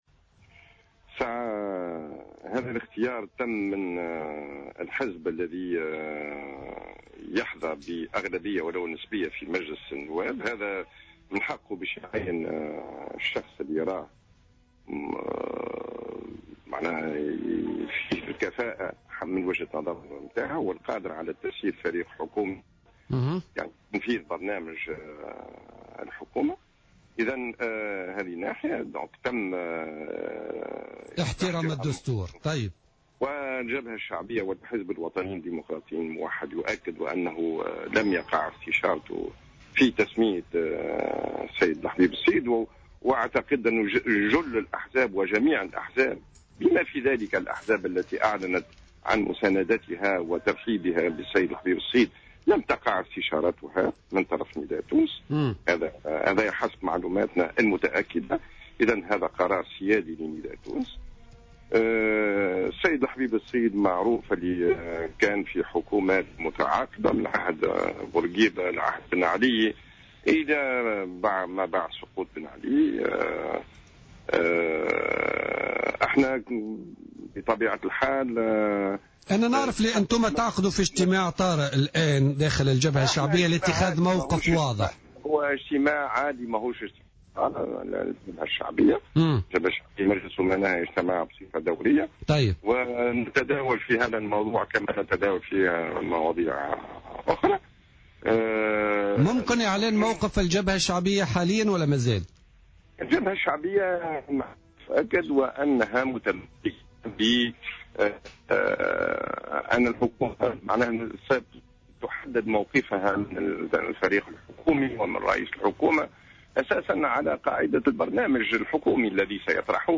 Mohamed Jmour, leader du parti Al Watad, membre du Front Populaire a commenté sur les ondes de Jawhara FM la désignation d’Habib Essid à la tête du nouveau gouvernement.